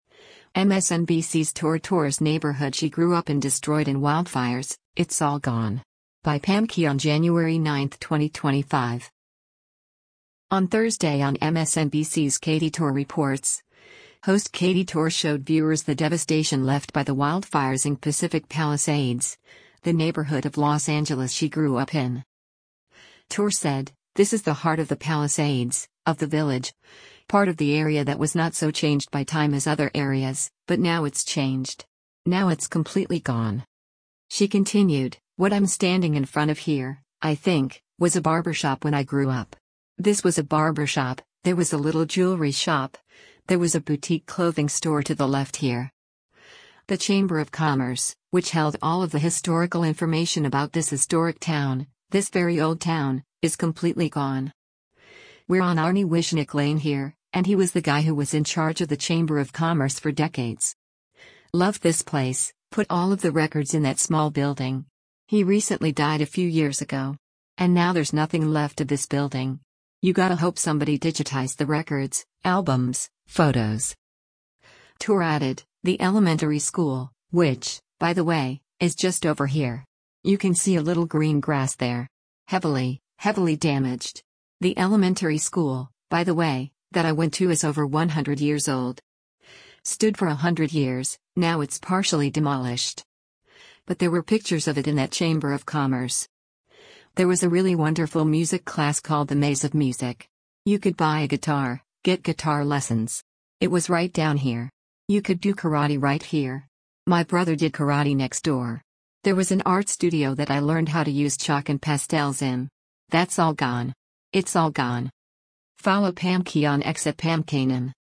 On Thursday on MSNBC’s “Katy Tur Reports,” host Katie Tur showed viewers the devastation left by the wildfires in Pacific Palisades, the neighborhood of Los Angeles she grew up in.